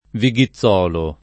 [ vi g i ZZ0 lo ]